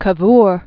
(kə-vr, kä-vr), Conte Camillo Benso di 1810-1861.